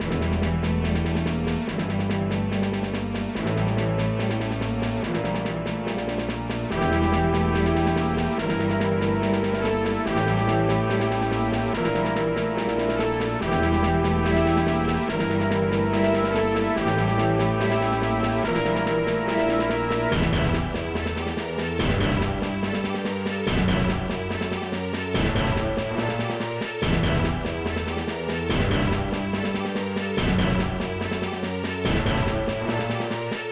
mod (ProTracker MOD (6CHN))
\samples\brass-me.sam
\samples\orchhit4.sam